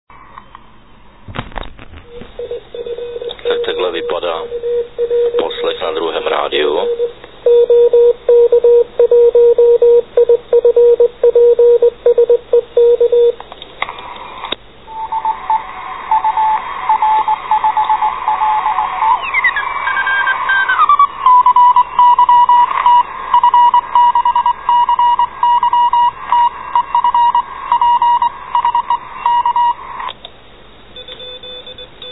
Zvuk 3   ( 64 KB )     Poslech signálu Trapera na FT817